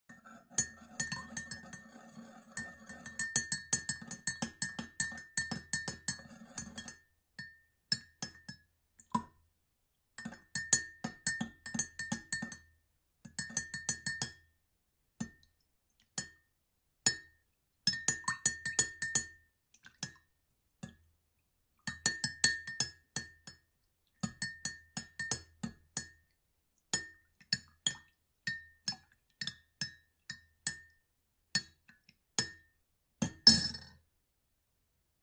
Tiếng Khuấy, đánh nước trong Ly nhẹ nhàng hơn…
Thể loại: Tiếng động
Description: Tiếng khuấy ly, tiếng đảo nước, tiếng quấy nước, tiếng đánh đồ uống, tiếng xoay nước, tiếng thìa chạm ly, âm thanh “loảng xoảng” nhỏ hoặc “lách tách” êm dịu khi thìa hoặc dụng cụ khuấy chạm vào thành ly, tạo những gợn sóng và tiếng nước chuyển động mềm mại. Âm thanh tinh tế, thư giãn, thường gợi cảm giác yên bình trong bếp, quán cà phê hoặc khung cảnh thưởng thức đồ uống.
tieng-khuay-danh-nuoc-trong-ly-nhe-nhang-hon-www_tiengdong_com.mp3